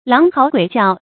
狼嗥鬼叫 láng háo guǐ jiào 成语解释 形容声音凄厉。